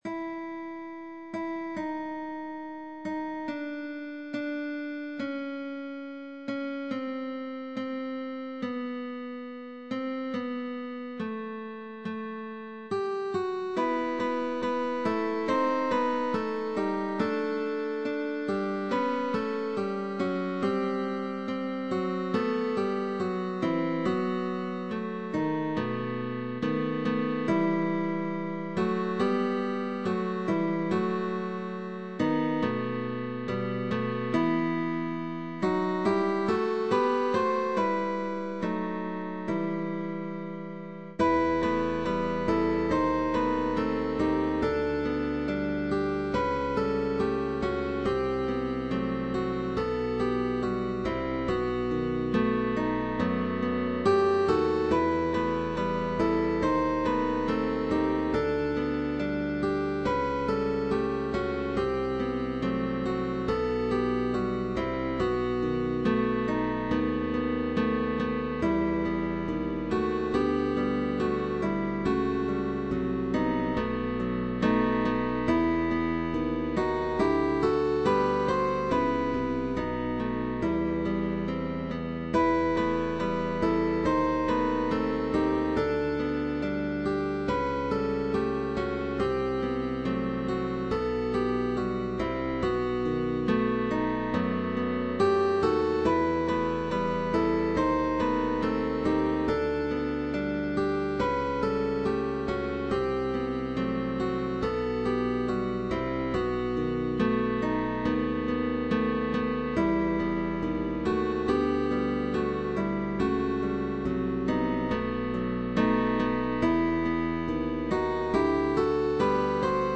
TRIO de GUITARRAS